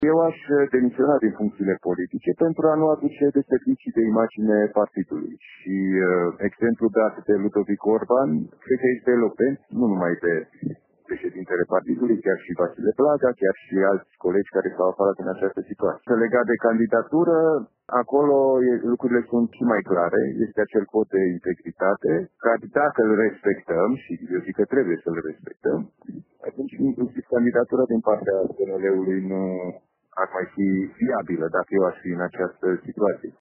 La rândul său, primarul din Dudeștii Noi, Alin Nica, fost contracandidat al lui Robu la șefia PNL Timiș, susține că în actualele condiții, primarul Nicolae Robu nu ar mai trebui să candideze pentru un nou mandat din partea PNL: